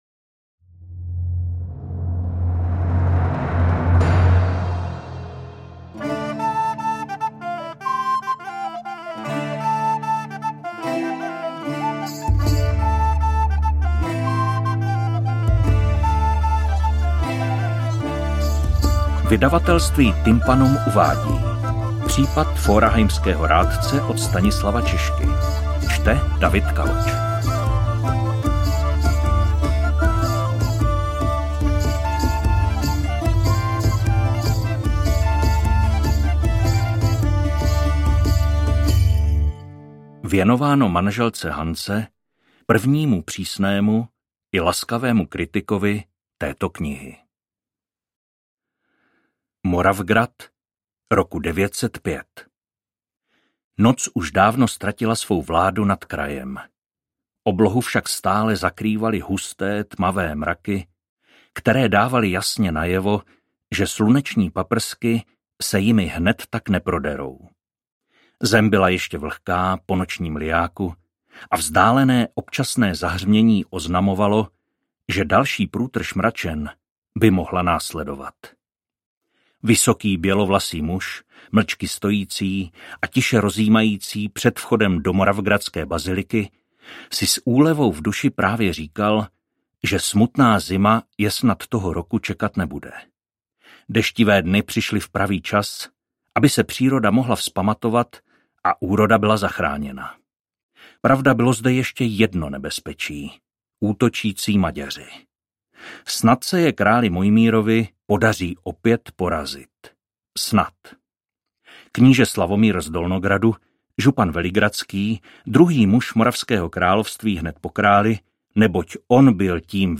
• AudioKniha ke stažení Případ forchheimského rádce